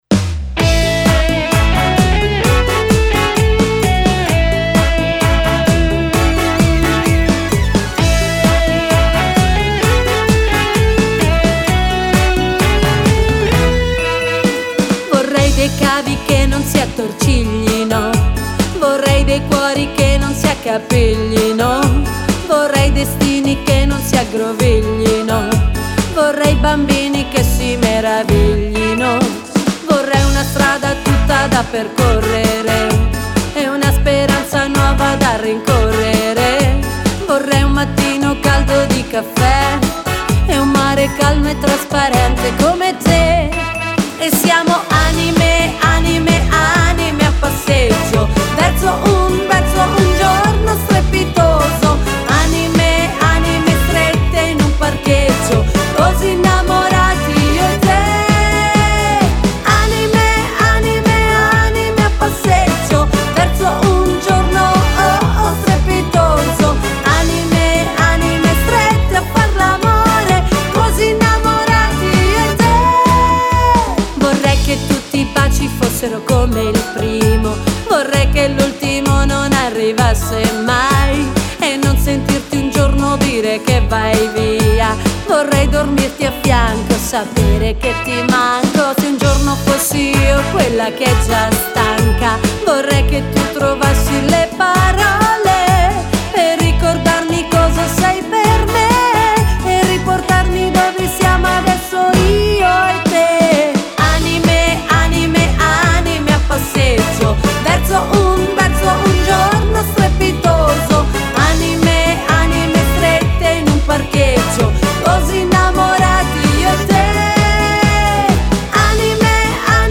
Cha cha cha